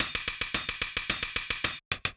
UHH_ElectroHatB_110-02.wav